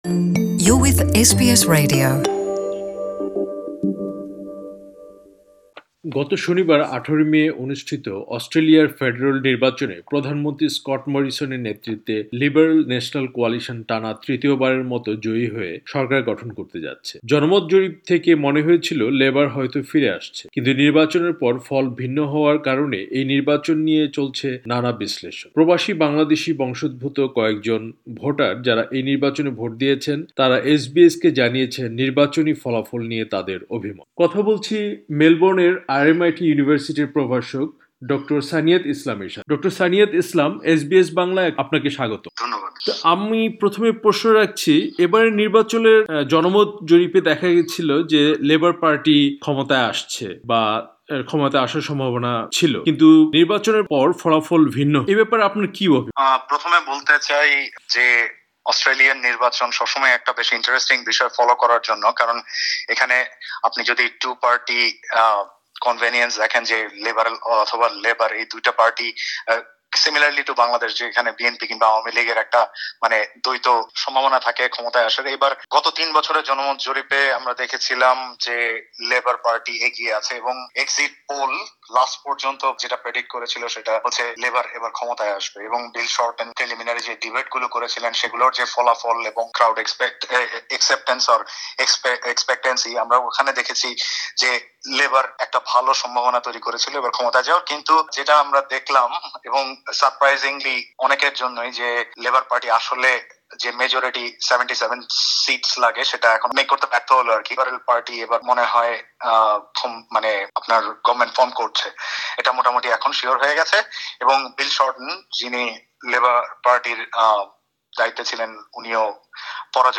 সাক্ষাতকারটি বাংলায় শুনতে ওপরের লিঙ্কে ক্লিক করুন।